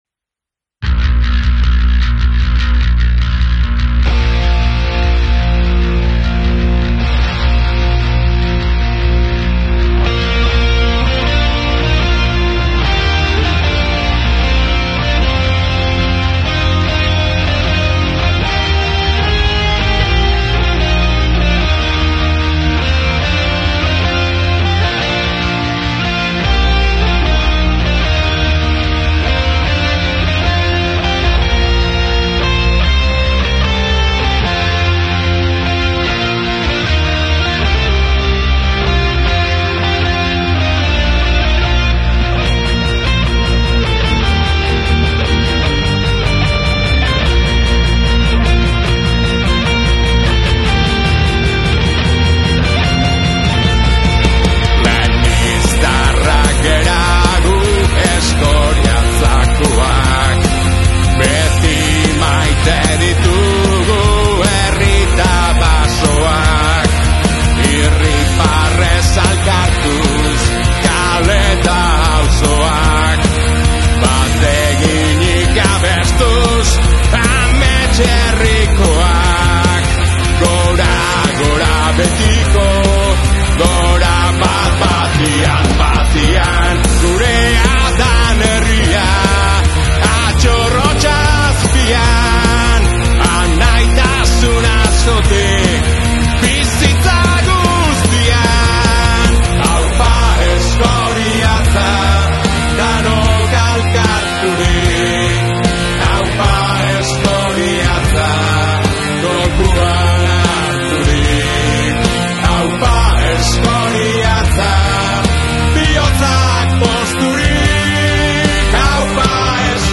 Rock estiloan ere grabatu da
Eskoriatzako ereserkia zortziko konpasean eginda dago eta orain arte bandarako, abesbatzarako, txistuarekin jotzeko…egokituta zegoen. Orain, rock estiloan  ere entzungai dago, bertsio berritzaile eta modernoagoan.